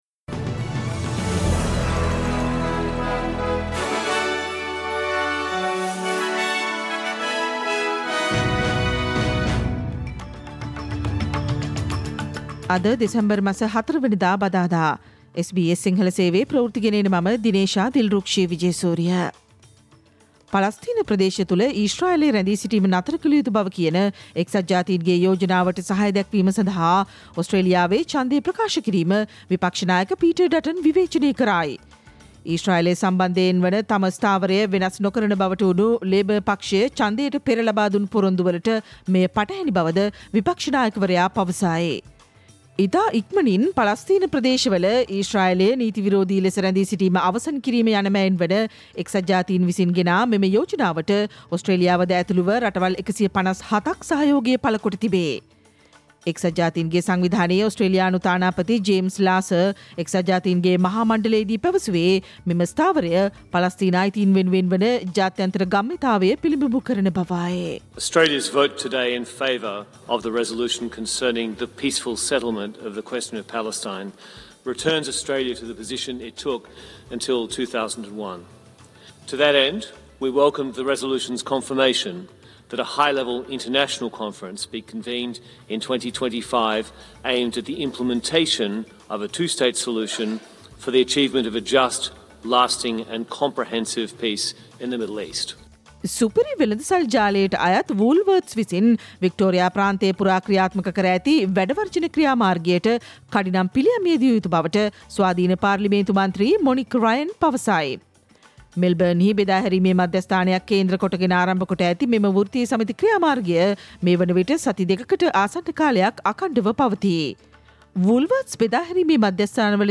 Australian news in Sinhala, foreign and sports news in brief - listen, to the SBS Sinhala radio news on Wednesday 20 December 2024